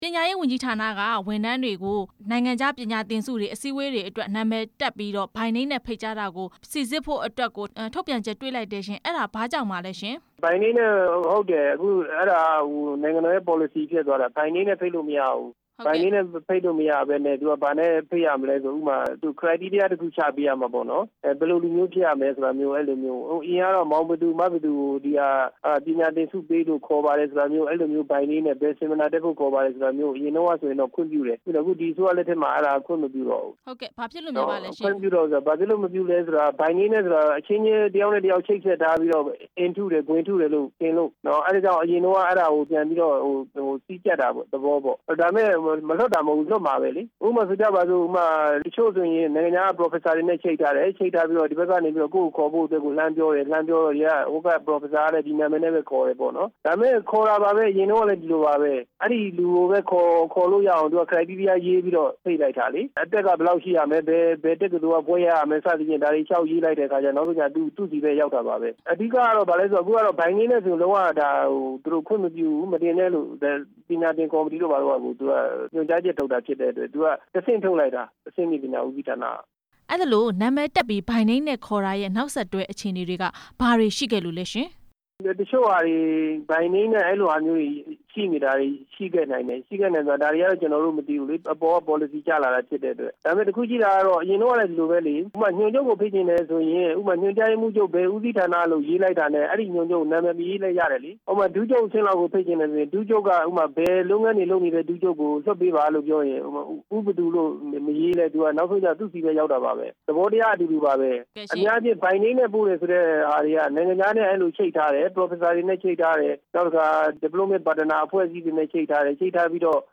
ပညာရေးဝန်ထမ်းတွေရဲ့ ပြည်ပခရီးစဉ်အကြောင်း ဆက်သွယ်မေးမြန်းချက်